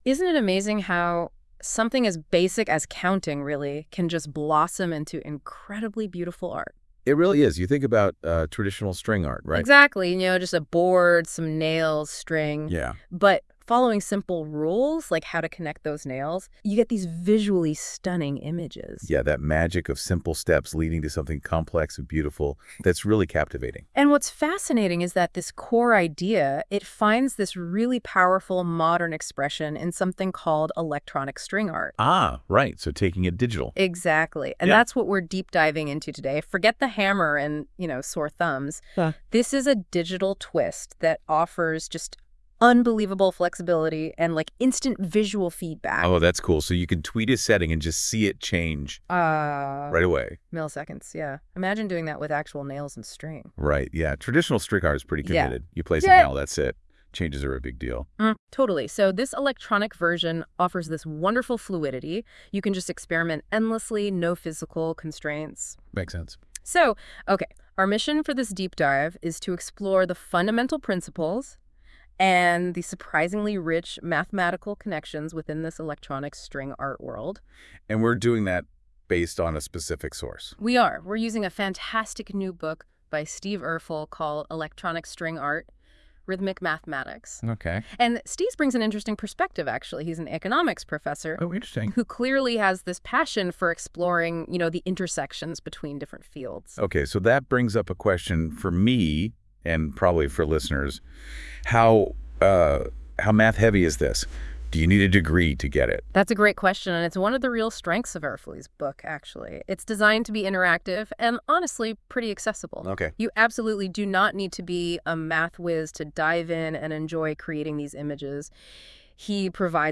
Or you can listen to this AI generated podcast.
This 17 minute podcast was AI generated using ESA as the document of record and I would say it is about 80-90% accurate (it could not deal with equations: so n = 12 comes off as n12, n-J comes off as nJ, and n/2 is called n2), but overall, I think it is pretty amazing.)